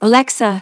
synthetic-wakewords
ovos-tts-plugin-deepponies_Celestia_en.wav